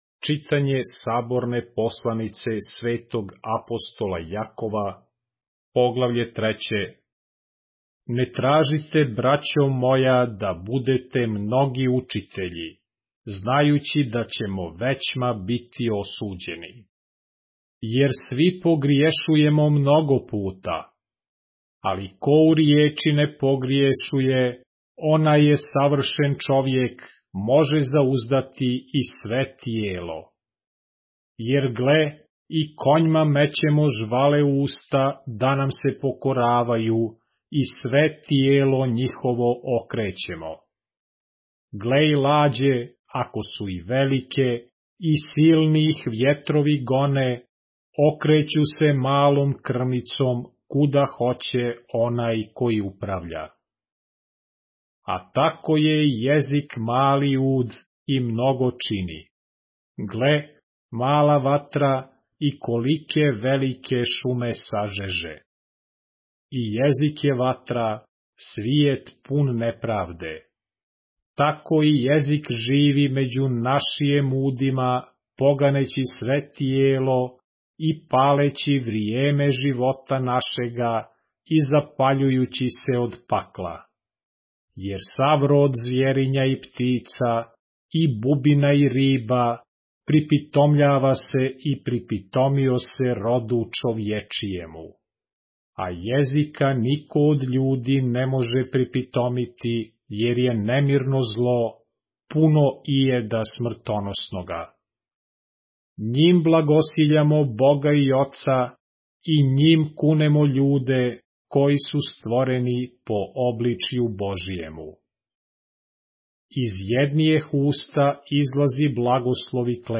поглавље српске Библије - са аудио нарације - James, chapter 3 of the Holy Bible in the Serbian language